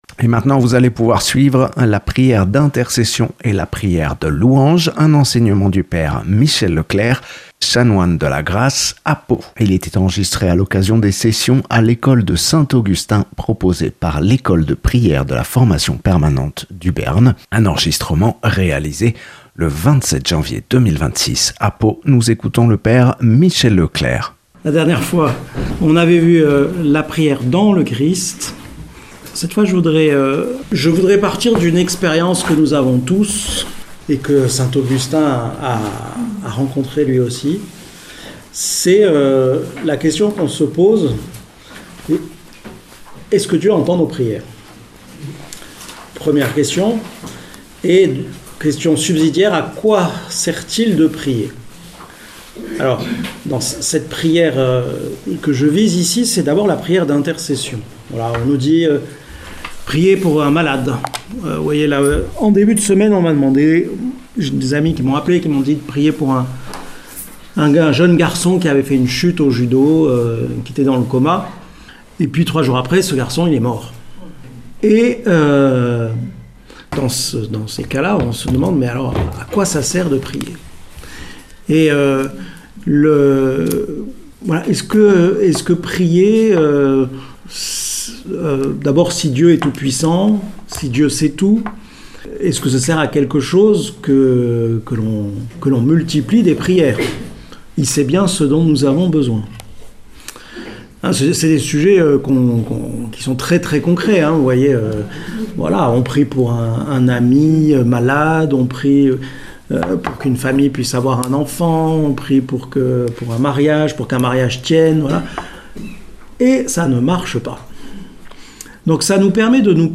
Une conférence
(enregistré à Pau le 27 janvier 2026)